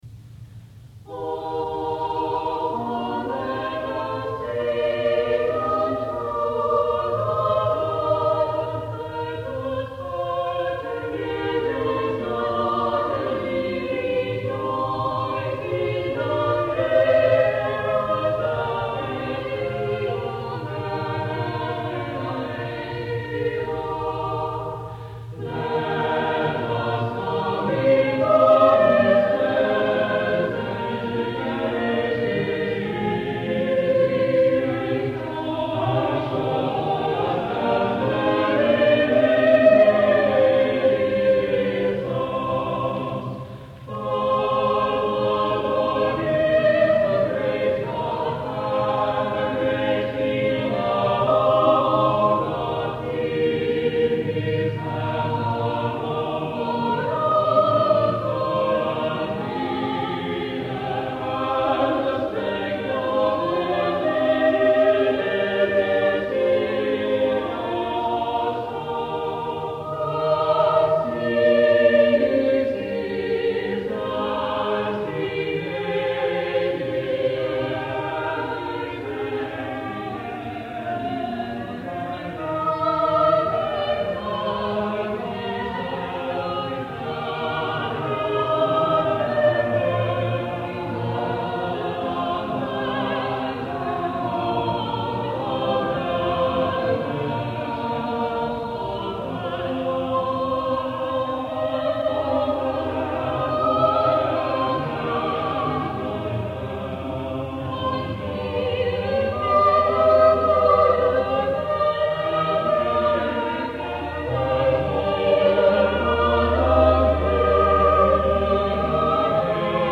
Although set to a text of similar length the “Venite” is far less elaborate and grand than the “Magnificat” above, although it does manage to enlist as many as 6-voice parts (to ‘Magnificat’s’ 8).